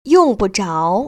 [yòng ‧bu zháo] 융부자오